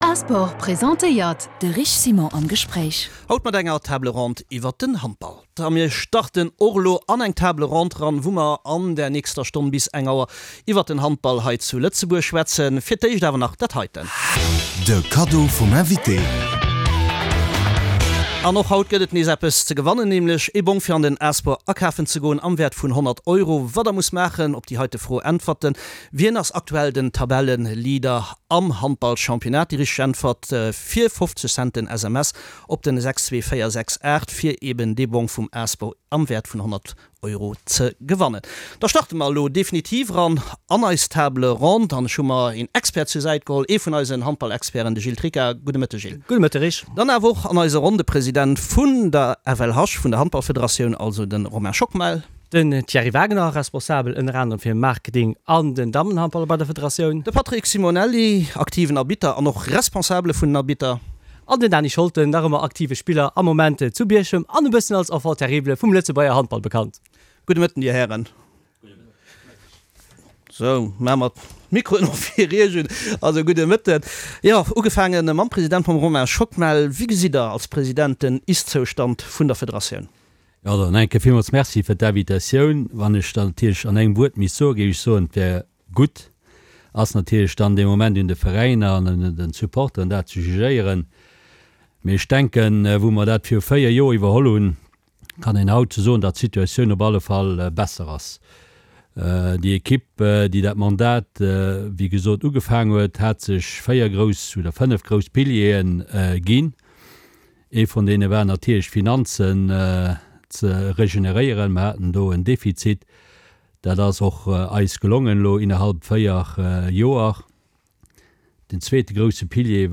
Wou steet den Ament de Lëtzebuerger Handball a wou wëll een hin? An enger Table Ronde hu mir mat de Leit déi um Terrain aktiv sinn diskutéiert.